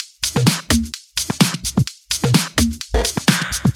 Index of /VEE/VEE Electro Loops 128 BPM
VEE Electro Loop 157.wav